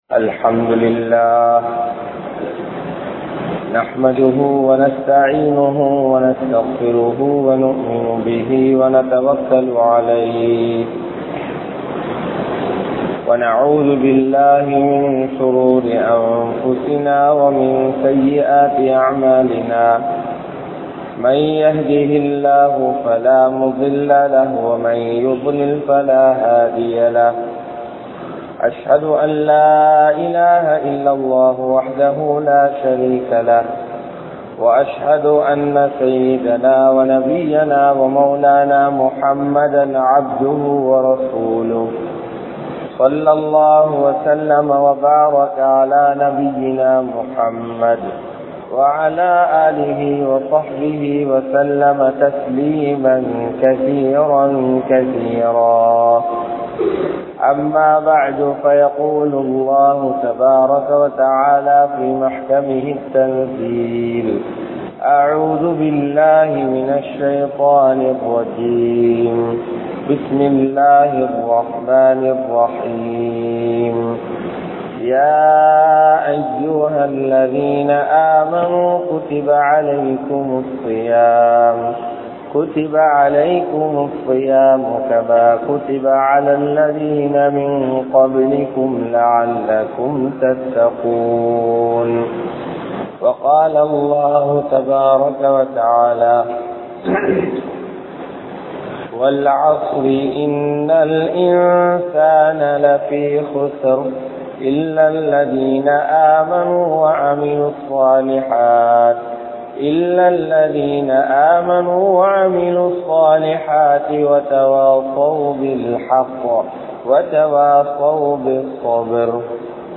Ramalan Maathaththai Evvaru Kalippathu? (ரமழான் மாதத்தை எவ்வாறு கழிப்பது?) | Audio Bayans | All Ceylon Muslim Youth Community | Addalaichenai
Kaluthura, Hilru (Oorukkul Palli) Jumua Masjidh